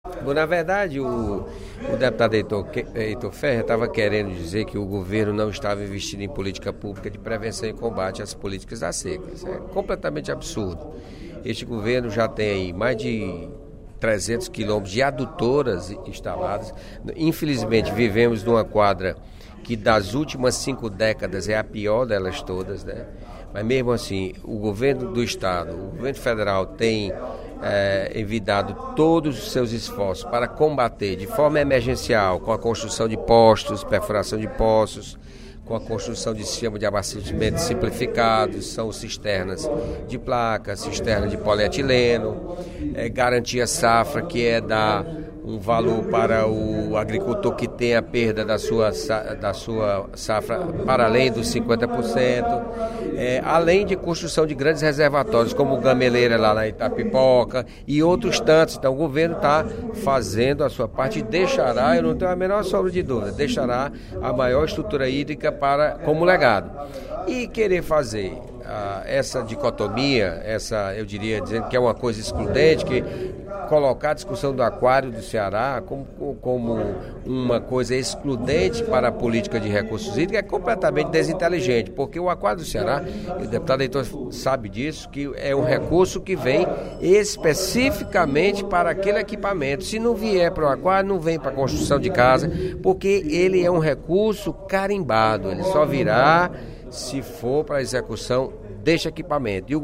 Na sessão plenária desta terça-feira (11/02), o deputado José Sarto (Pros), líder do Governo do Estado na Assembleia Legislativa, esclareceu que os recursos provenientes de financiamento para o Acquario do Ceará têm destinação exclusiva.